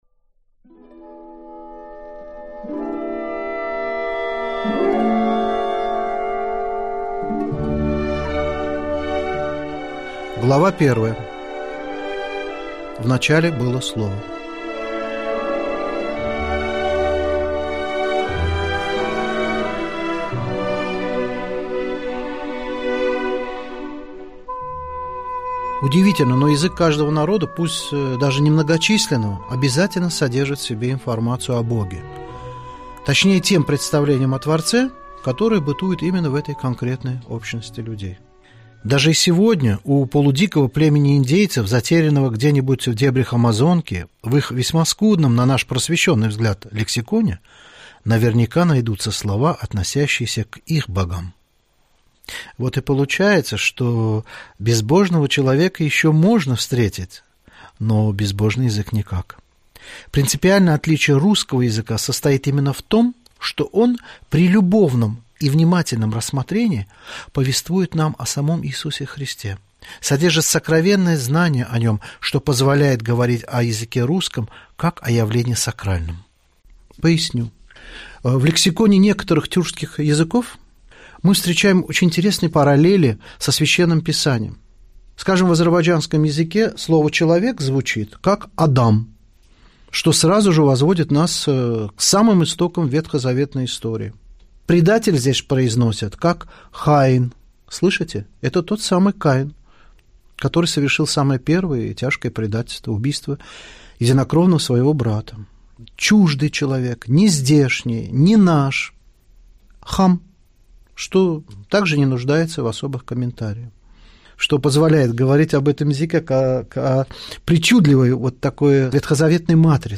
Аудиокнига Тайна русского слова | Библиотека аудиокниг